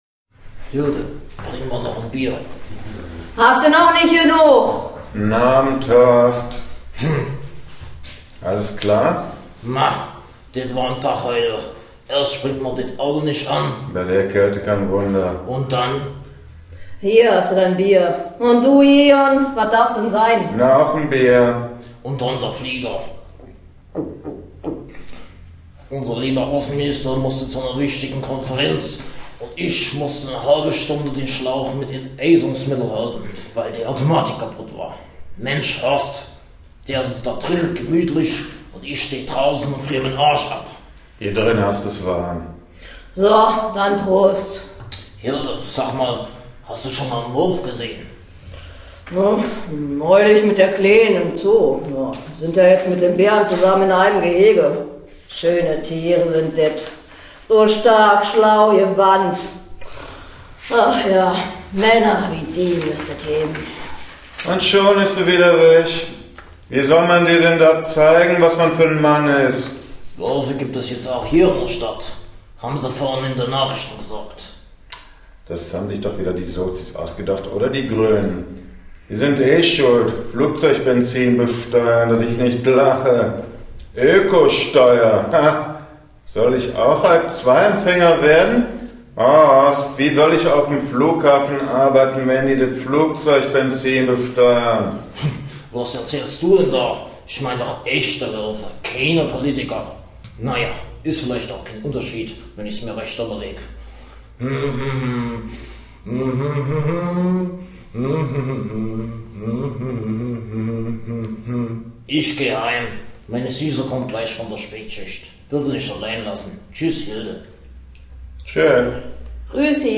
Kneipe
kneipe.mp3